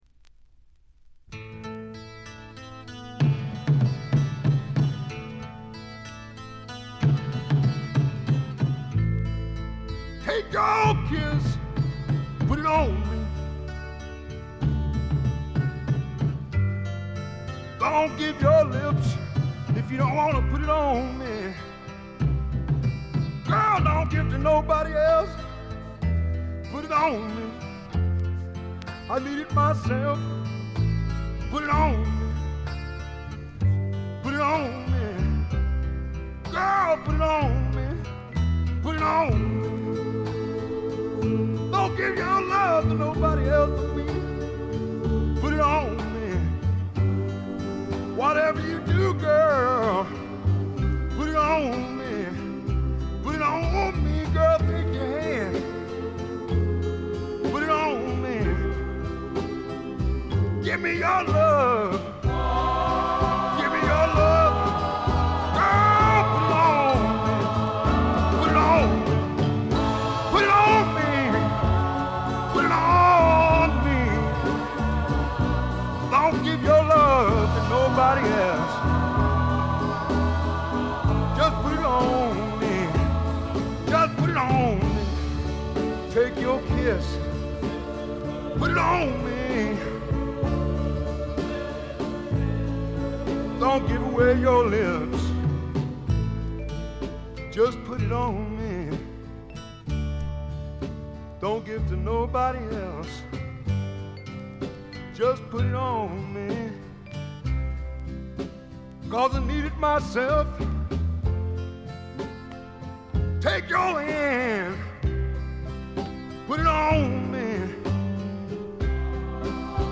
テキサス産スワンプポップの名作。
試聴曲は現品からの取り込み音源です。